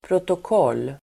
Uttal: [protok'ål:]